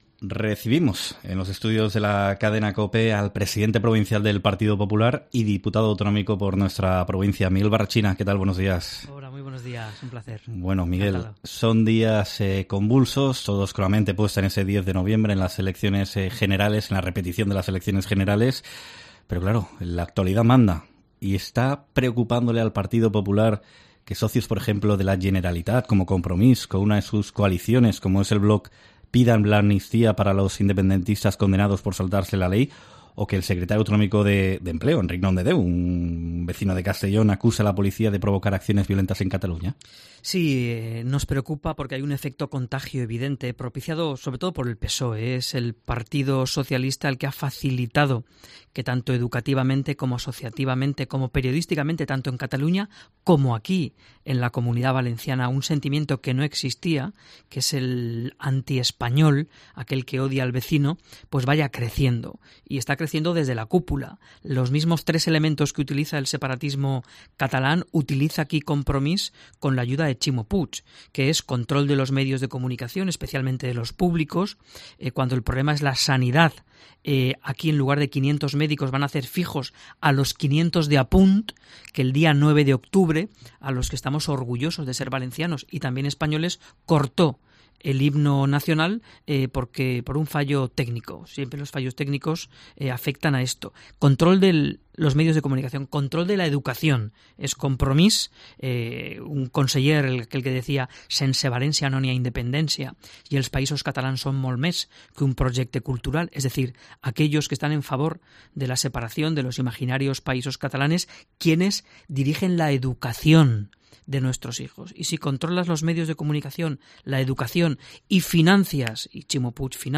AUDIO: Entrevista en COPE a Miguel Barrachina, presidente del PP en Castellón.